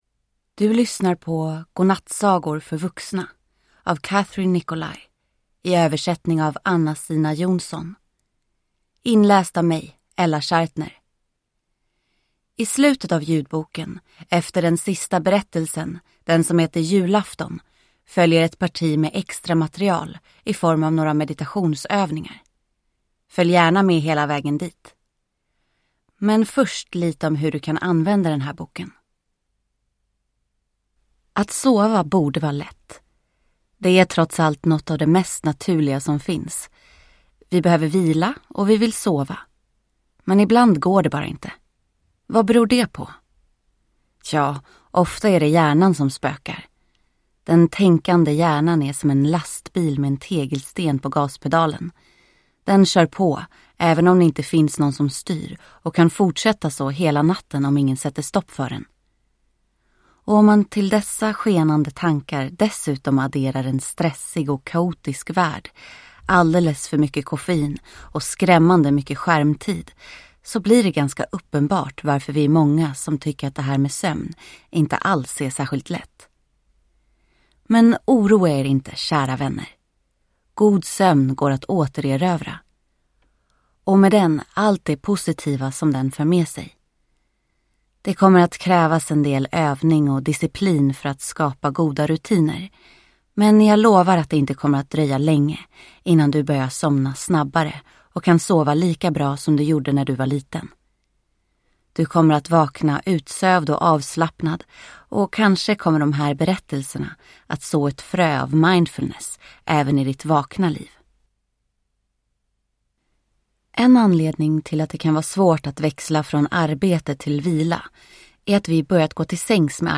• Ljudbok